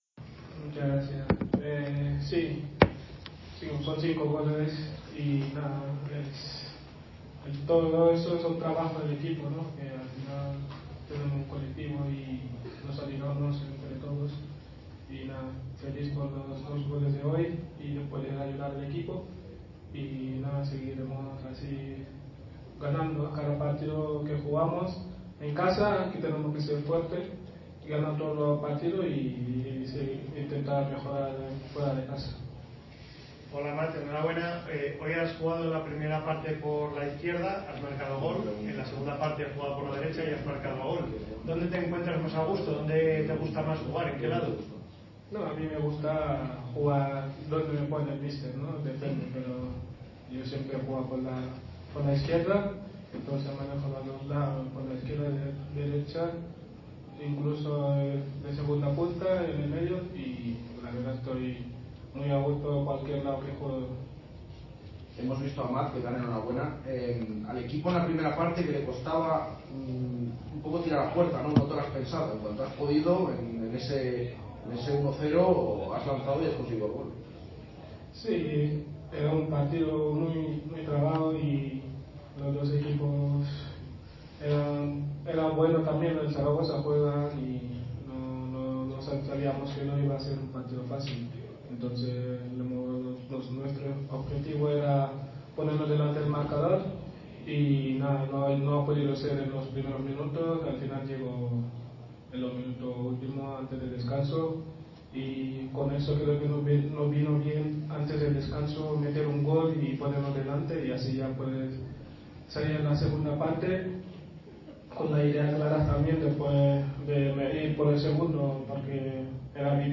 Sus goles hoy han supuesto tres puntos ante el Real Zaragoza aunque en sala de prensa ha puesto el foco en el equipo y, obviamente, también en lo feliz que se siente al marcar con la blanquivioleta después de su paso por la cantera vallisoletana.